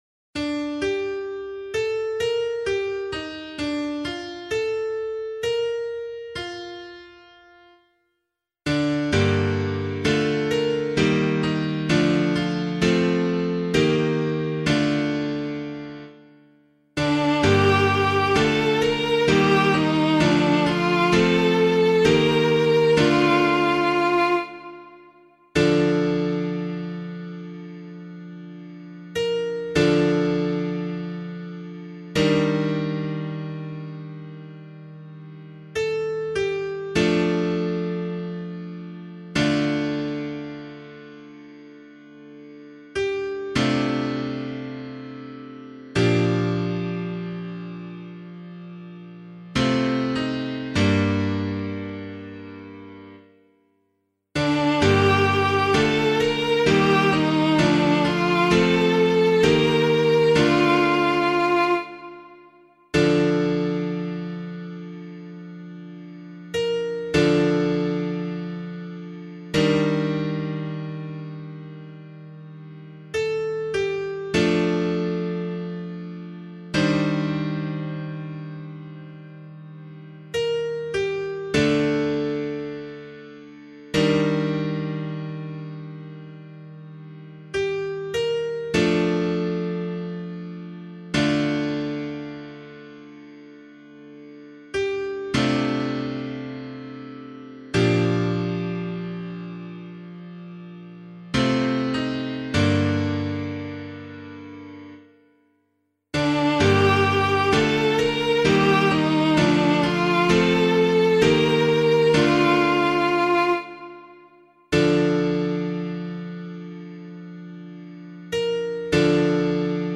piano • d vocal